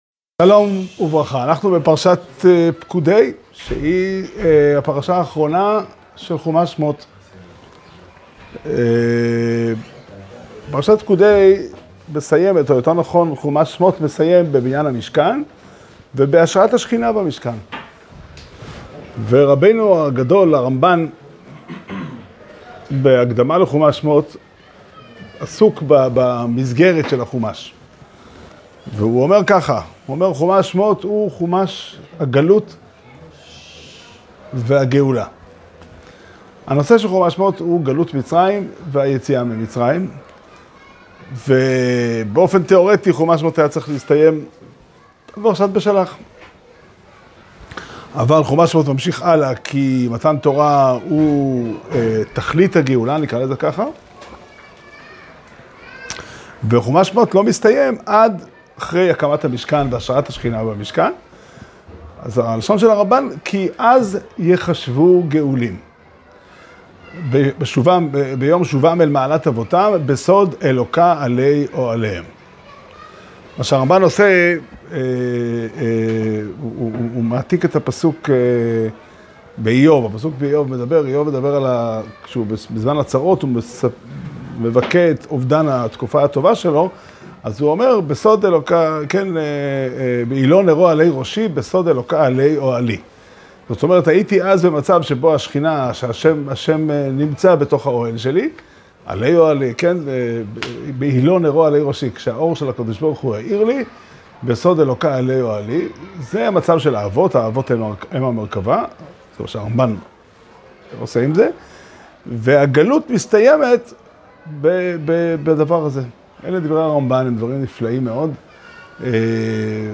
שיעור שנמסר בבית המדרש פתחי עולם בתאריך ל' אדר א' תשפ"ד